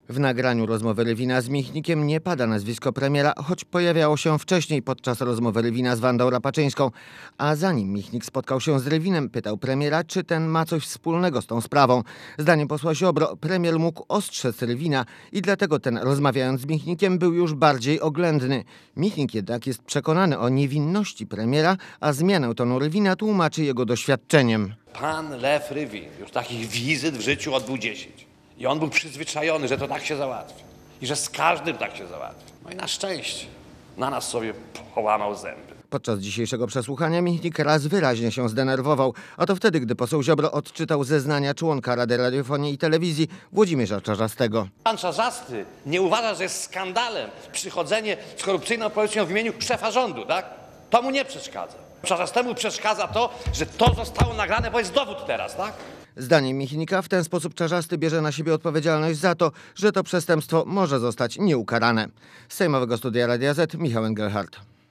Posłuchaj relacji reportera Radia Zet i wypowiedzi Adama Michnika (1,98 MB)